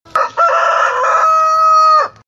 rooster.wav